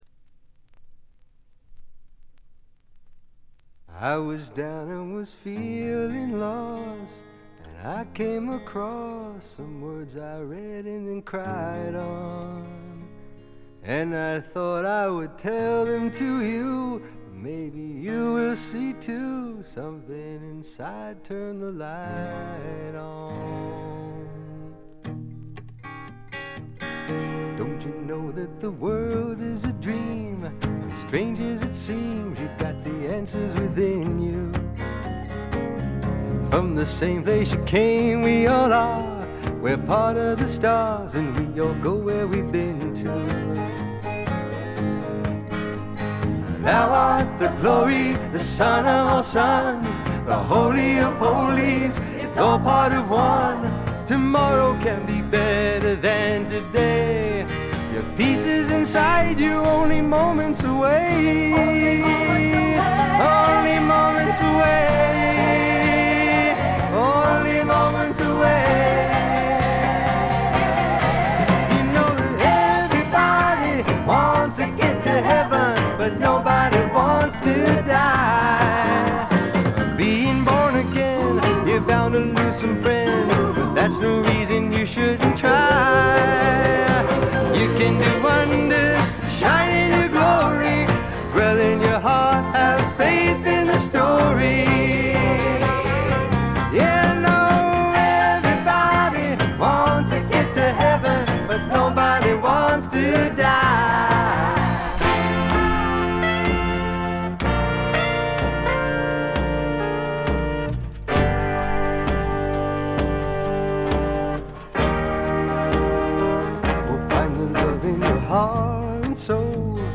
微細なチリプチがほんの少し。
試聴曲は現品からの取り込み音源です。
こちらもご覧ください　 レコード：米国 SSW / フォーク